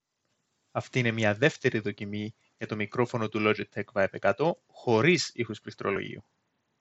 • Type: Dual omni-directional MEMS mics with directional beamforming and DSP
Ακολουθούν δύο δοκιμές, με και χωρίς ήχο πληκτρολογίου στο background.
Test 2 – No Keyboard Typing
No-Keyboard-2.mp3